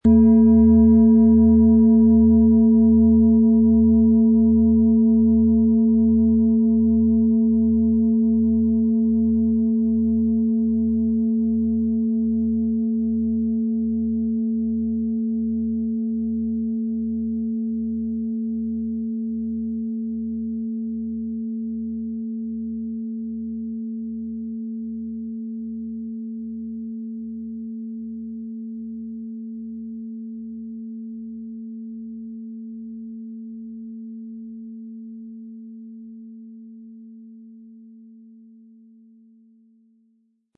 Diese Wasserstoffgamma Klangschale wurde in einem auf das Herstellen von Klangschalen spezialisierten Dorf in Indien von vielen Händen in mühevoller Handarbeit hergestellt.
• Mittlerer Ton: Mond
Im Audio-Player - Jetzt reinhören hören Sie genau den Original-Klang der angebotenen Schale. Wir haben versucht den Ton so authentisch wie machbar aufzunehmen, damit Sie gut wahrnehmen können, wie die Klangschale klingen wird.
Der passende Schlegel ist umsonst dabei, er lässt die Schale voll und harmonisch tönen.
PlanetentöneWasserstoffgamma & Mond
MaterialBronze